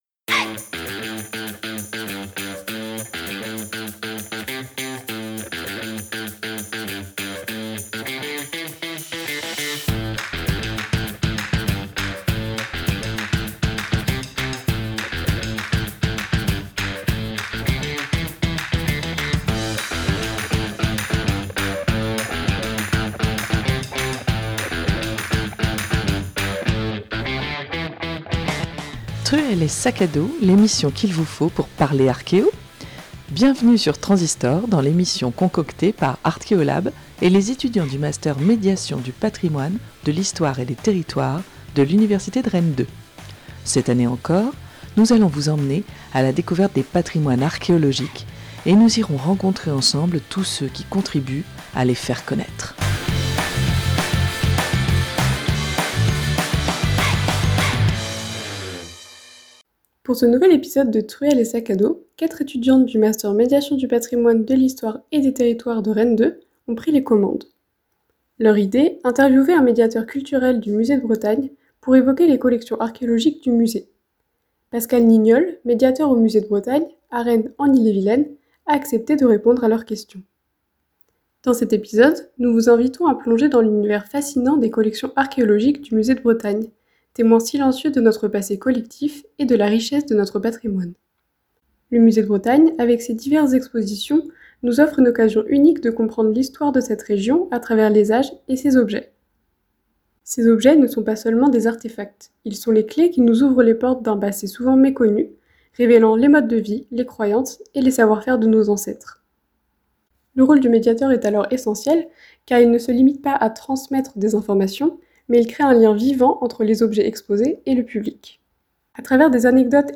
Dans cet épisode de Truelle et sac à dos, 4 étudiantes du master Médiation du patrimoine, de l'histoire et des territoires (MPHT) de Rennes 2 ont pris les commandes.